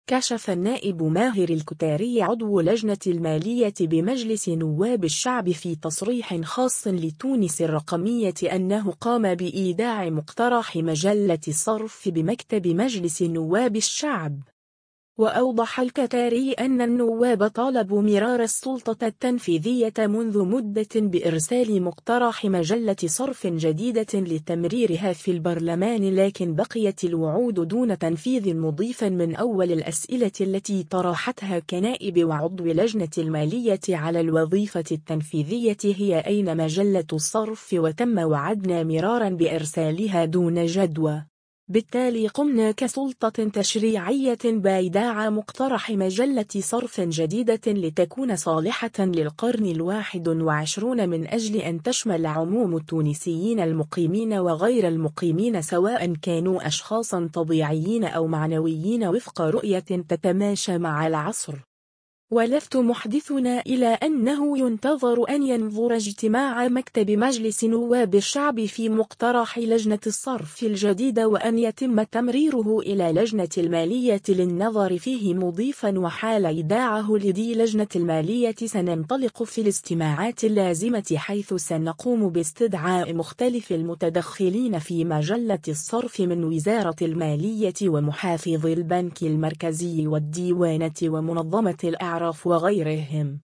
كشف النائب ماهر الكتاري عضو لجنة المالية بمجلس نواب الشعب في تصريح خاص لـ”تونس الرقمية” أنه قام بإيداع مقترح مجلة الصرف بمكتب مجلس نواب الشعب.